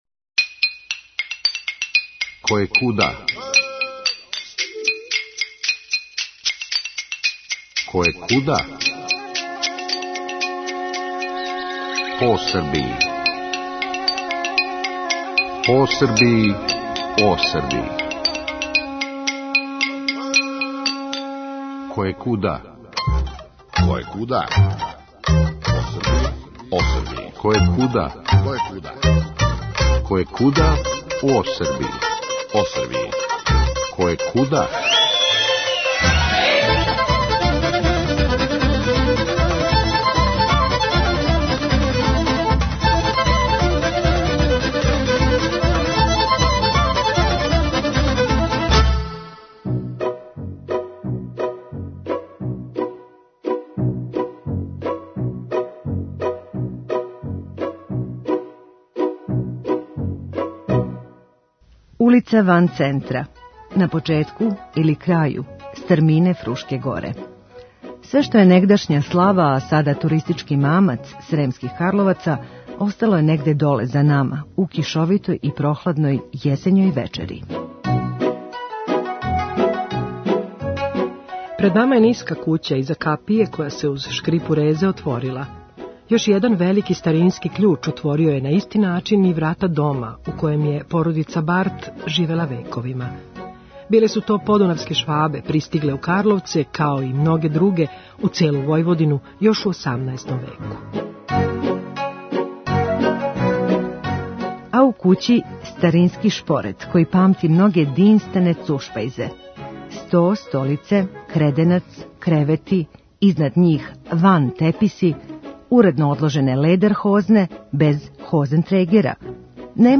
Пред нама је ниска кућа иза капије која се уз шкрипу резе отворила.